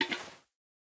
empty_powder_snow2.ogg